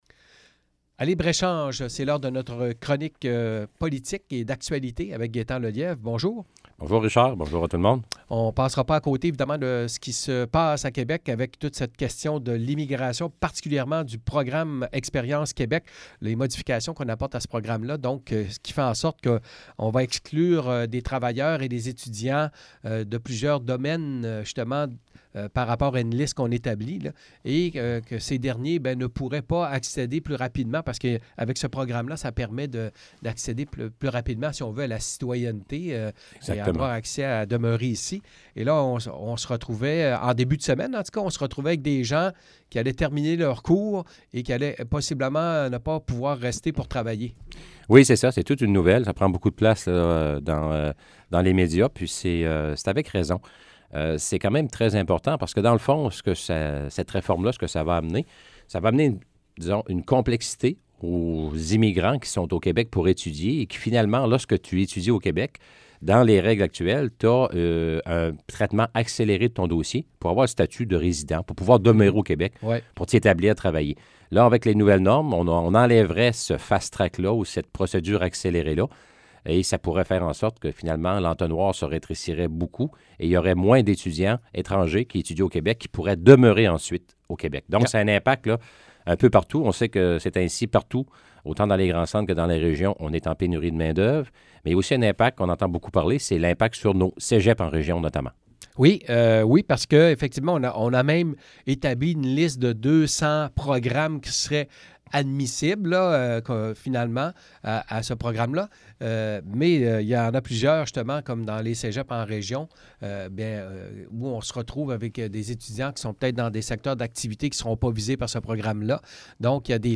Chronique politique avec Gaétan Lelièvre: